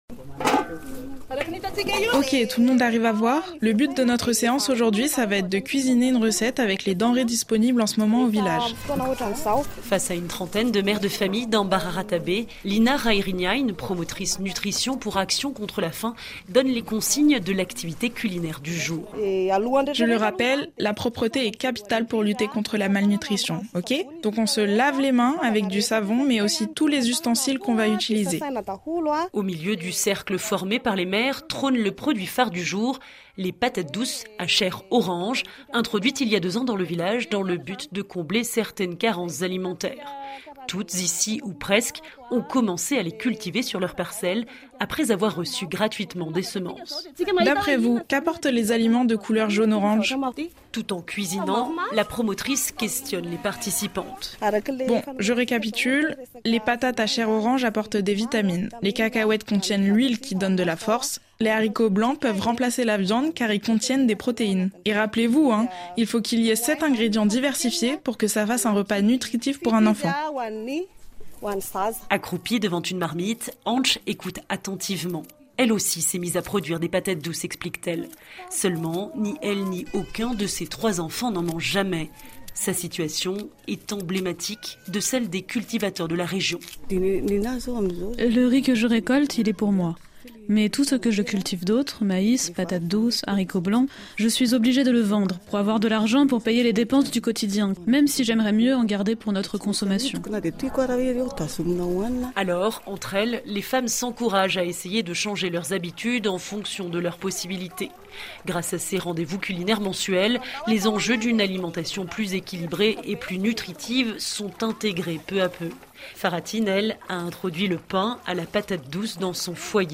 Reportage Afrique « »
De notre correspondante à Madagascar,